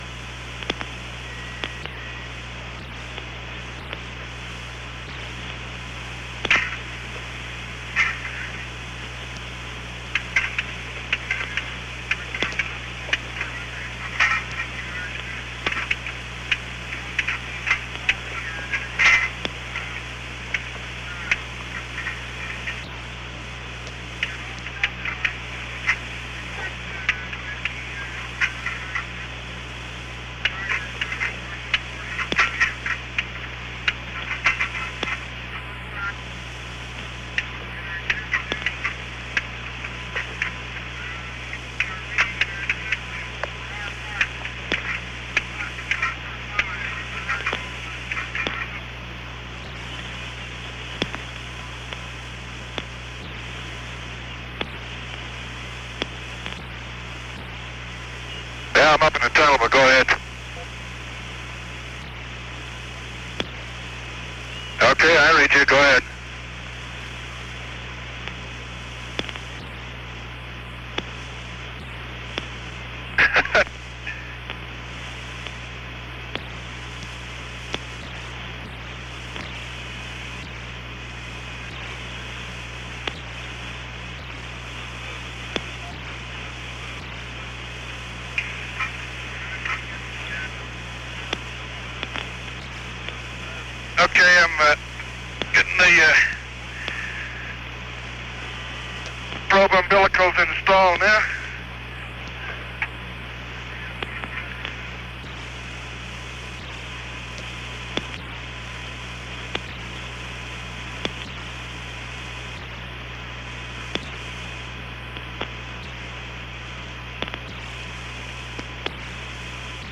It appears that long quiet periods have been deleted, probably by a process of copying from one tape machine to another.
However, this audio is being presented because it includes much metallic clanking as Ron works to reinstall the docking equipment.